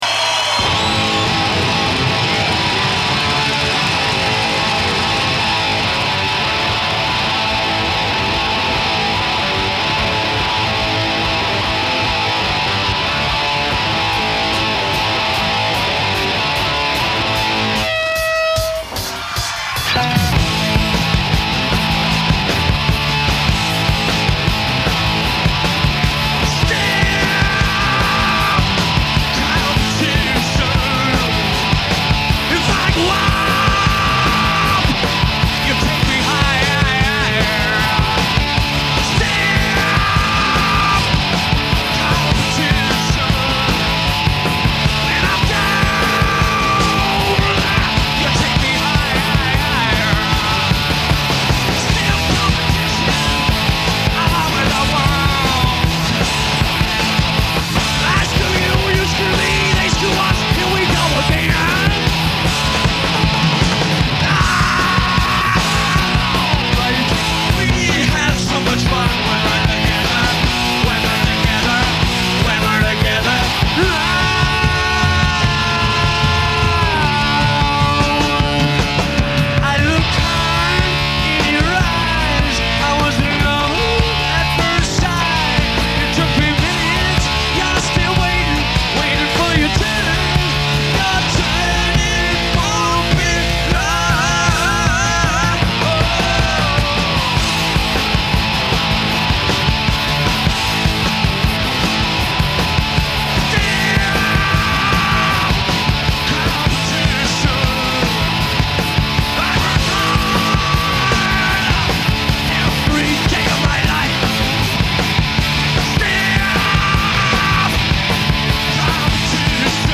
Album Reviews, Classic Rock, Genre, Hard Rock